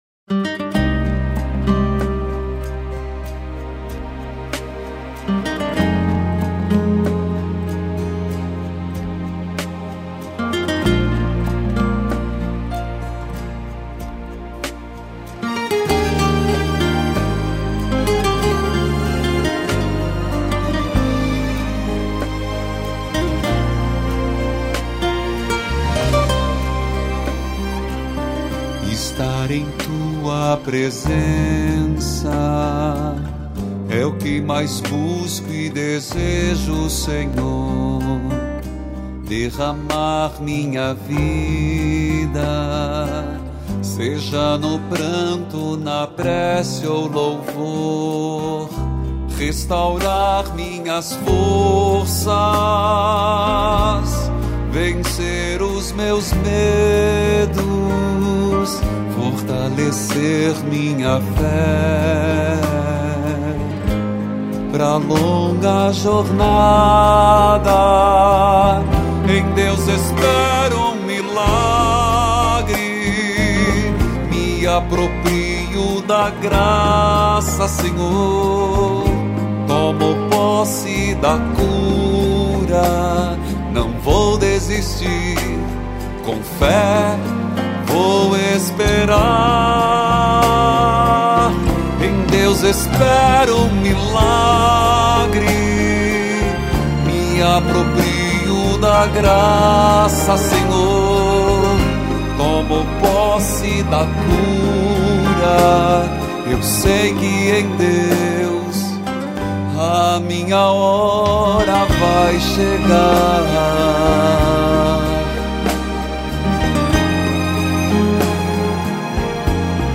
mais novo disco de estúdio